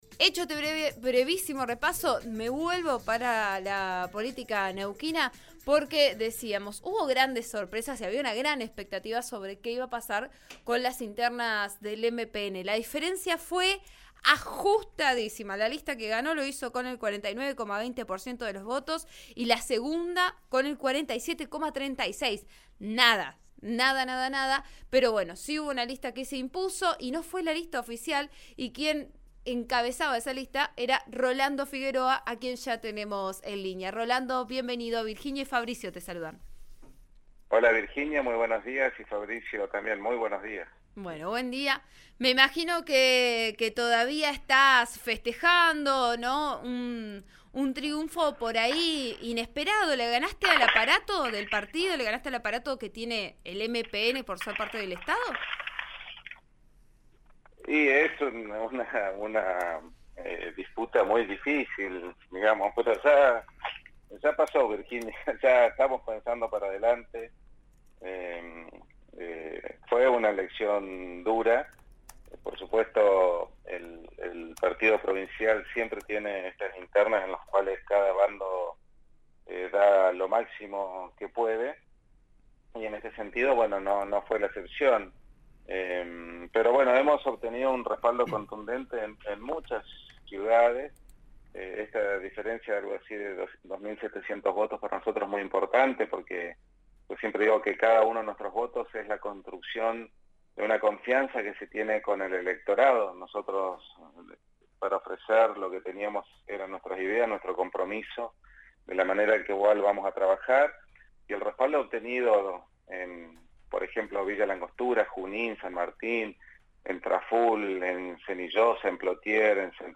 Se refirió a su relación con el gobernador Omar Gutiérrez, apelando a una metáfora deportiva, pero se mostró conciliador. Habló con RN Radio (89.3).